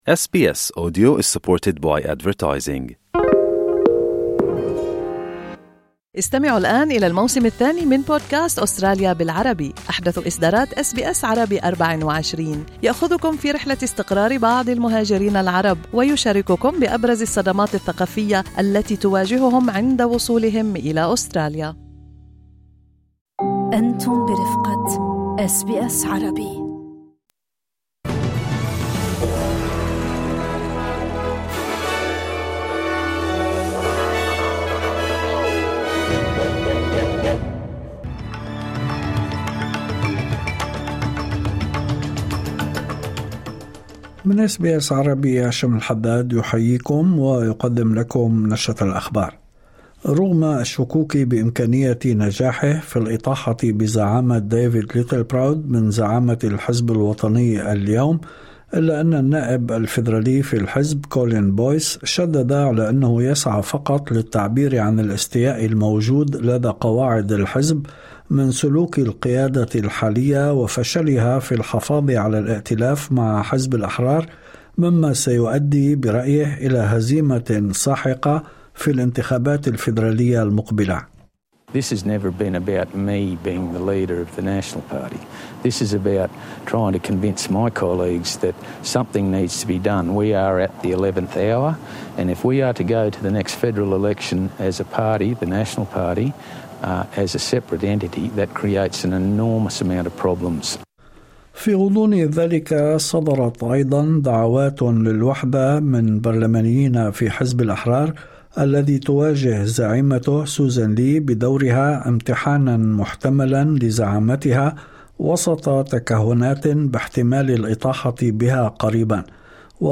نشرة أخبار الظهيرة 02/02/2026